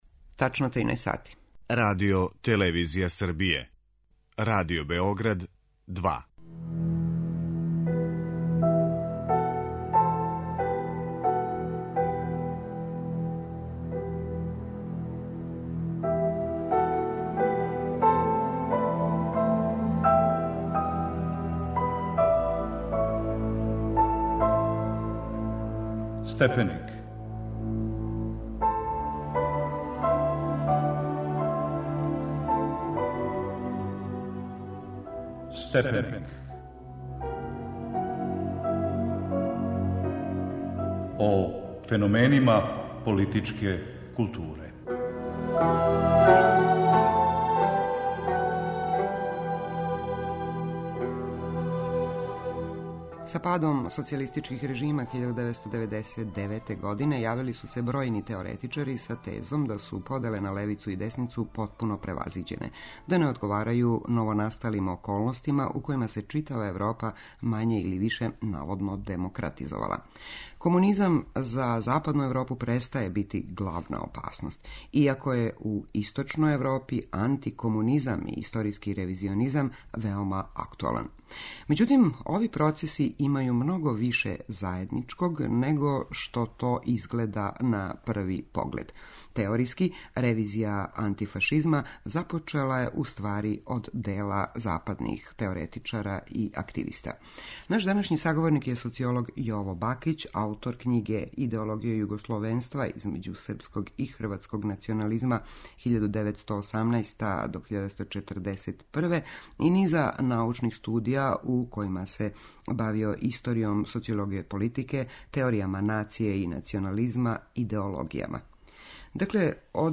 Аудио подкаст Радио Београд 2